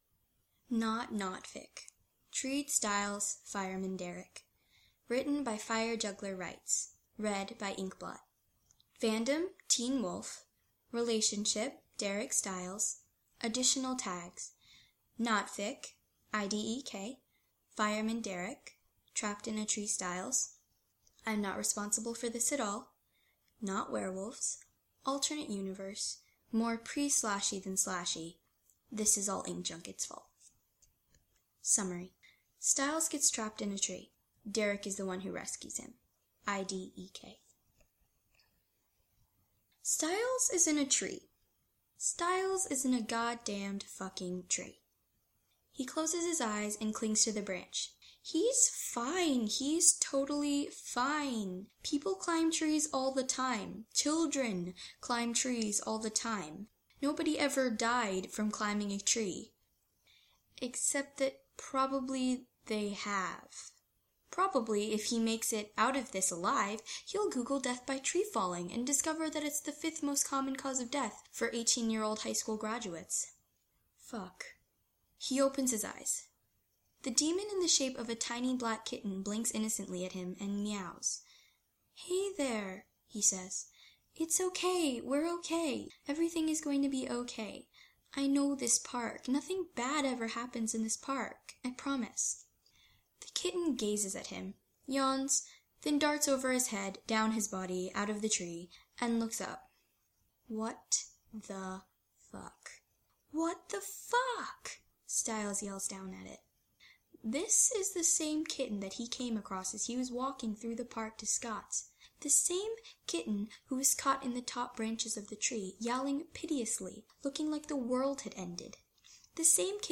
[podfic]